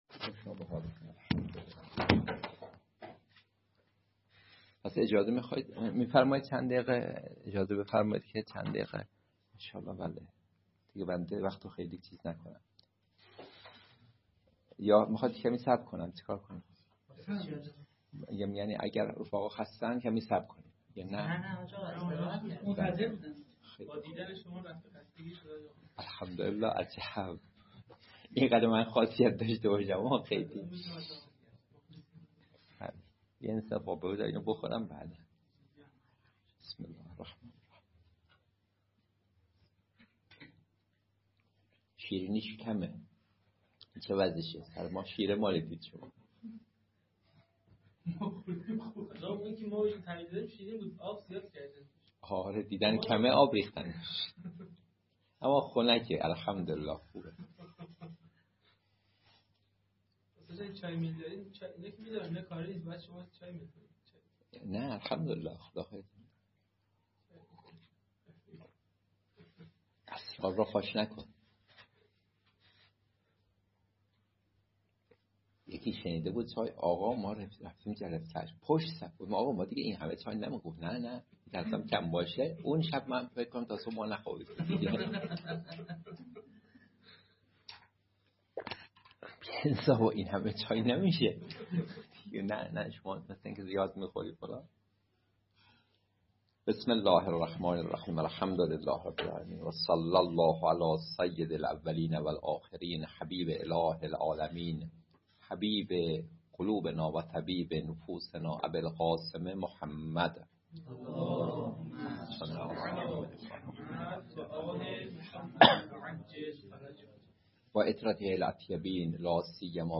درس الاخلاق
حیات طیبه سلوک مؤمنانه در زندگی مشترک محفل خانواده های محترم طلاب در مدرسه عالی امام حسین علیه السلام جلسه دوم : 📌📃 برای مطالعه متن و محتوای کلاس اینجا را کلیک کنید.